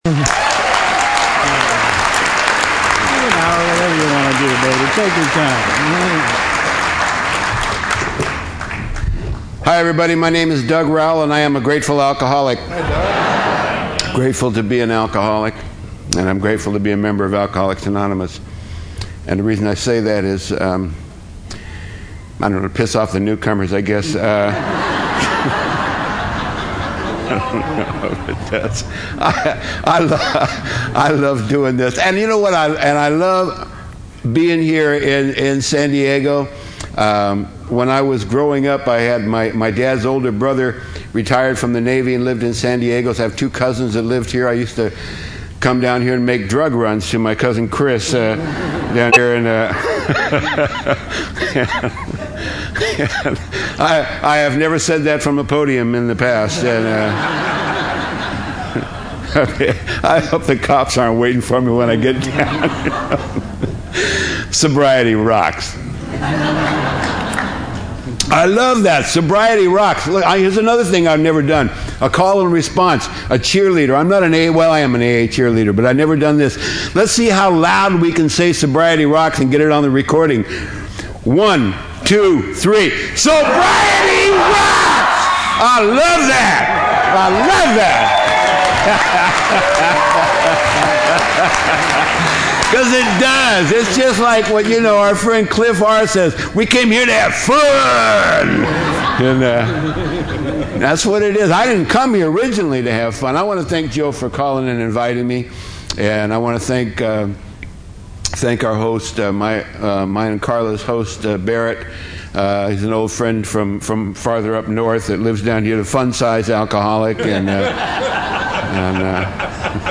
San Diego Spring Roundup 2010